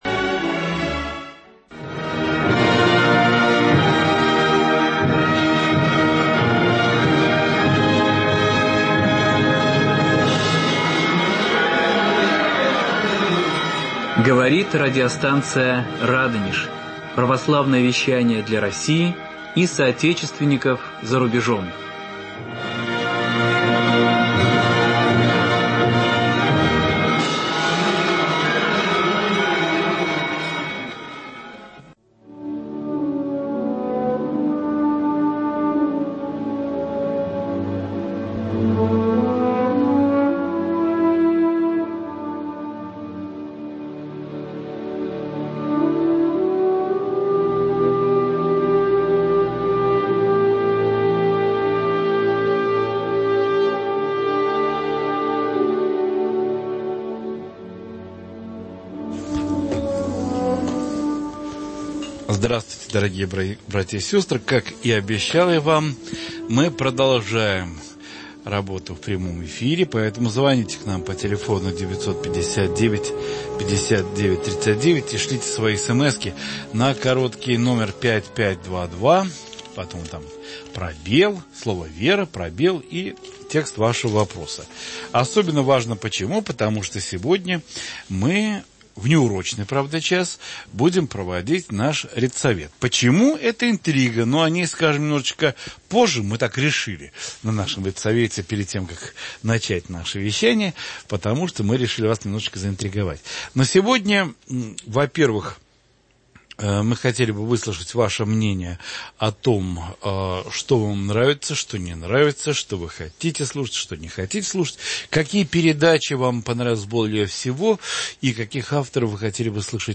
Прямой эфир:редколлегия р/с «Радонеж». Ответы и вопросы.